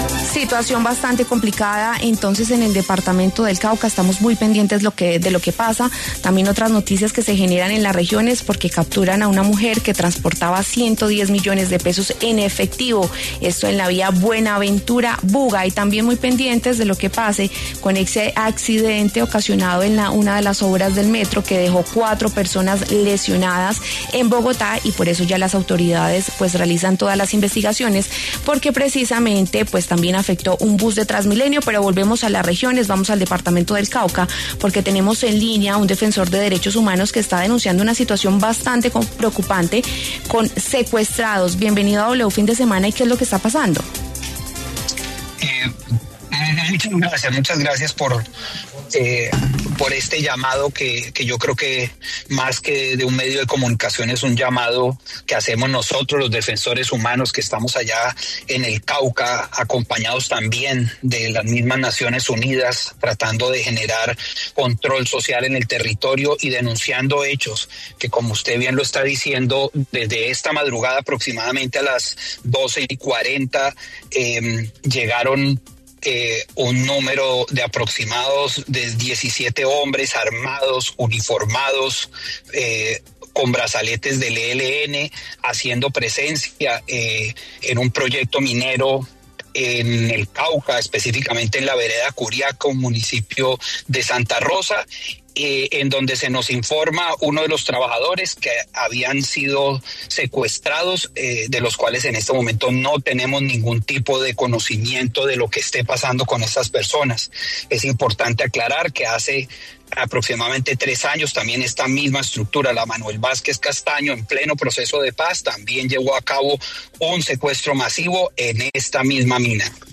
En entrevista en W Fin de Semana, un líder social y defensor de Derechos Humanos denunció el secuestro masivo, en las últimas horas, de más de 20 personas en zona rural del municipio de Santa Rosa, sur del Cauca por parte de un grupo armado ilegal.